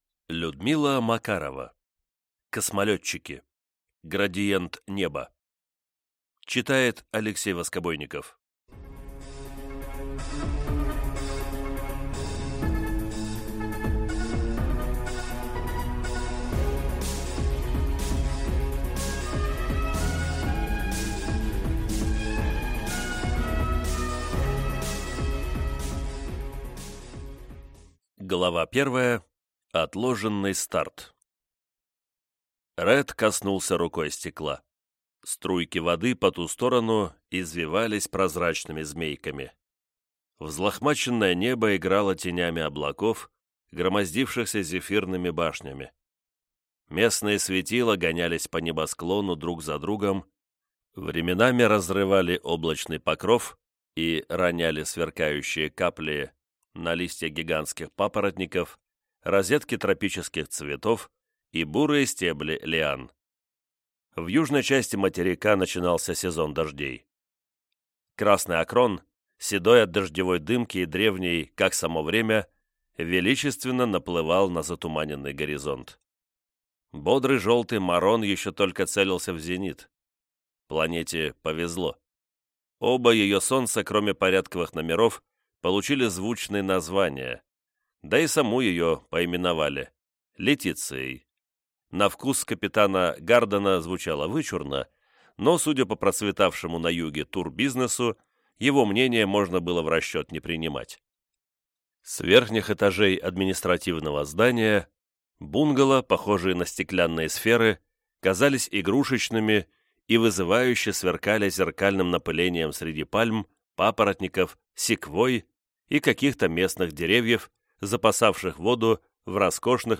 Аудиокнига Космолетчики. Градиент неба | Библиотека аудиокниг